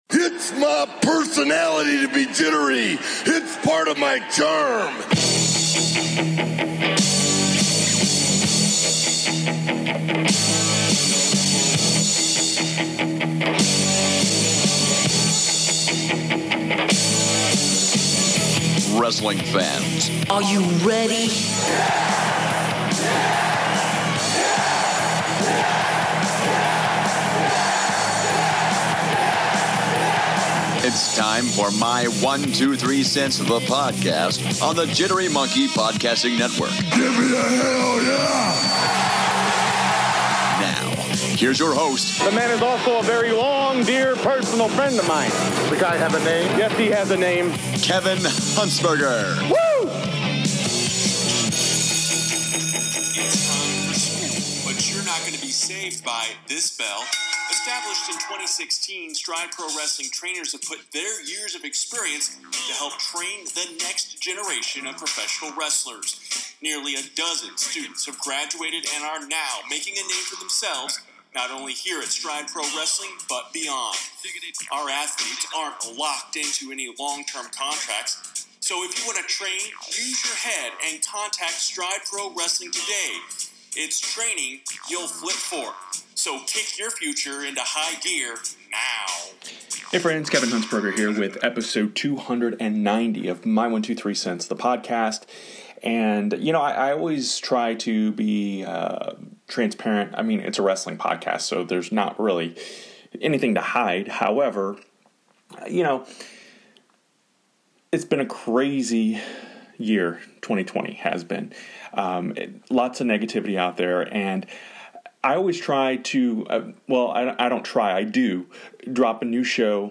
Today, in an effort to spread some positivity, I’m sharing the Cameo videos my friends sent me after I made the career change.